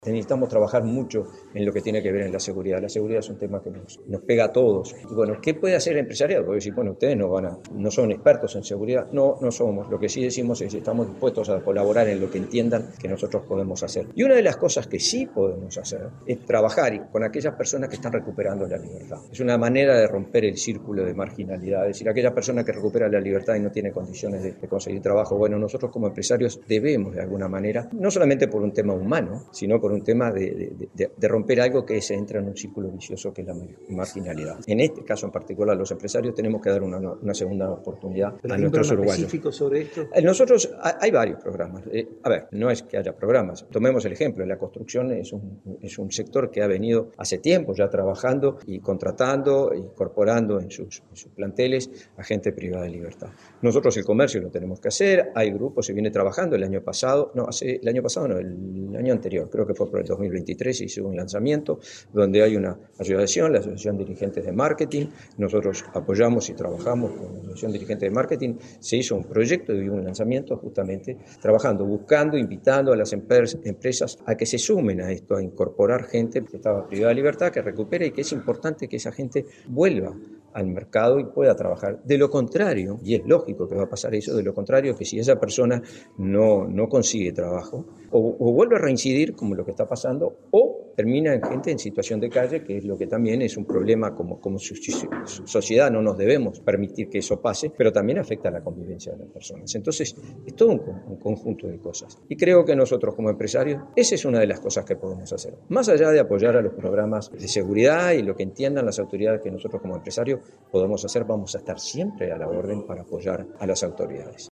El Encuentro se desarrolló el jueves 27 en el Centro Comercial e Industrial de Florida, en el marco de su 95° aniversario, con la presencia de autoridades de organizaciones de todo el territorio nacional.
Informe